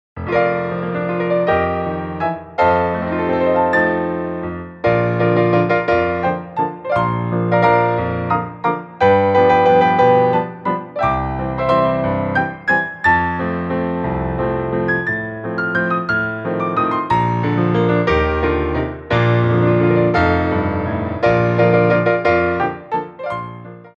Grand Allegro
3/4 (16x8)